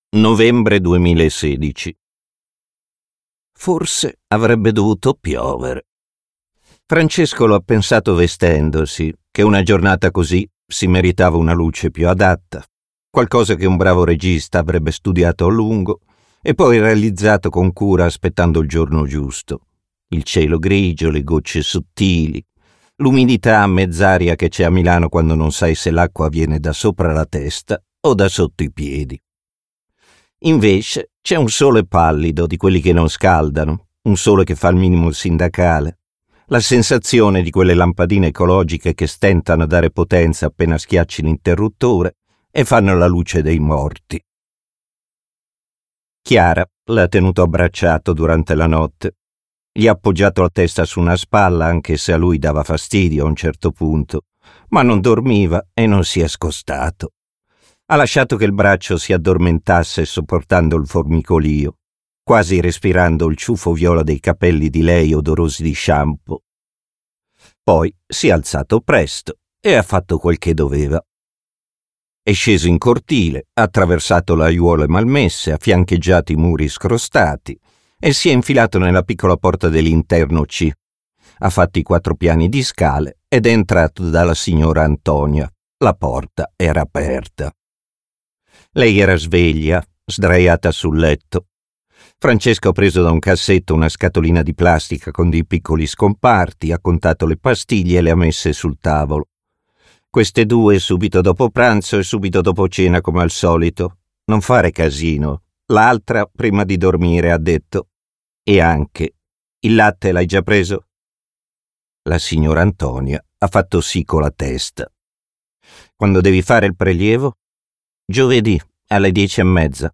letto da Gigio Alberti
Versione audiolibro integrale